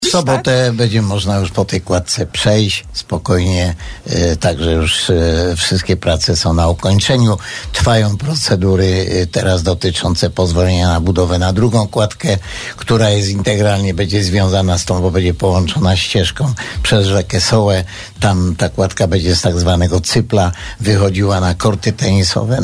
– W sobotę będzie można już po tej kładce przejść. Wszystkie prace są na ukończeniu – mówił dziś w Radiu Bielsko burmistrz Żywca Antoni Szlagor.